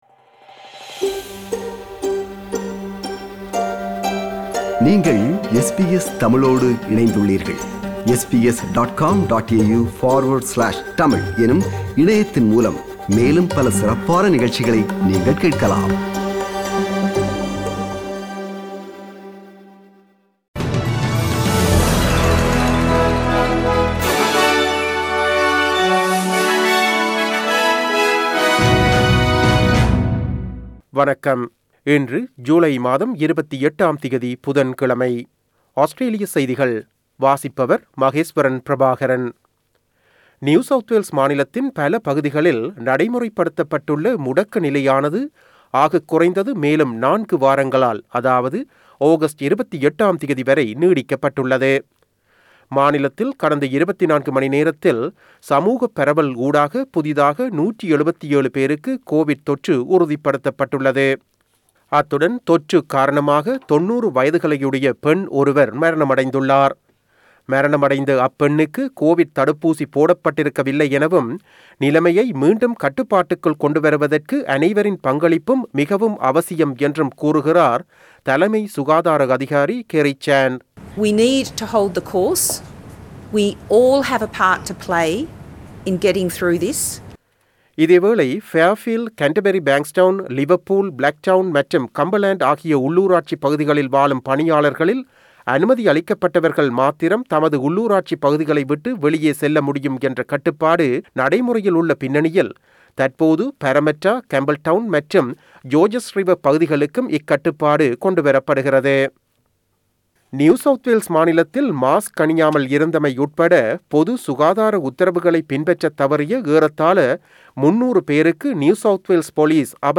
Australian news bulletin for Wednesday 28 July 2021.